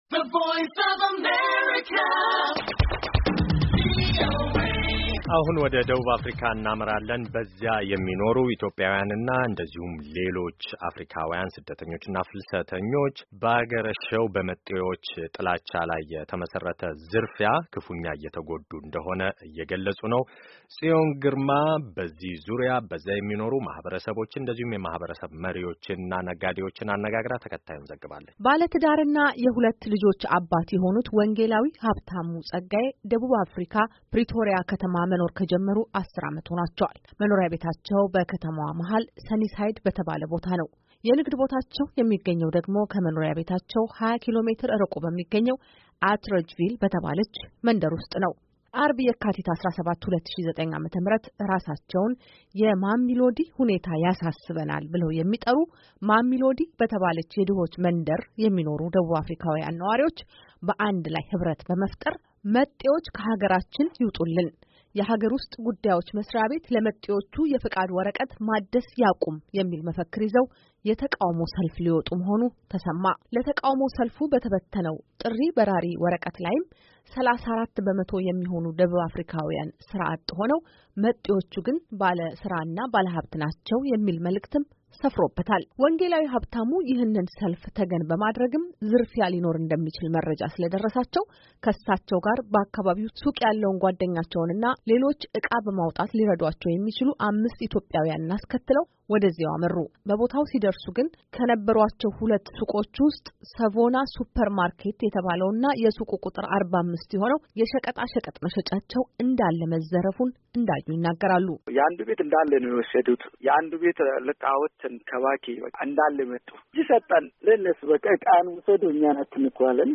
በደቡብ አፍሪካ ከሁለት ዓመት በኋላ በድጋሚ ባገረሸው በመጤዎች ጥላቻ ላይ ያነጣጠረ ዝርፊያ ፕሪቶሪያ "አትረጅቪል" በተባለች መንደር ውስጥ ሙሉ የሱቅ ንብረታቸውን እንደተሰረቁ ሁለት ኢትዮጵያውያን ለአሜሪካ ድምጽ ተናገሩ። በአንድ ዓመት ጊዜ ውስጥ ሙሉ ለሙሉ ሱቃችን ሲዘረፍ ይሄ ሁለተኛ ጊዜያቸው እንደሆነ የተናገሩት ነጋዴዎች “ሱቆቹን ያሟላነው ተበድረን ነበር። ዕዳውን ሳንከፍል ከነቤተሰቦቻችን ቧዶ እጃችንን ቀረን ብለዋል።”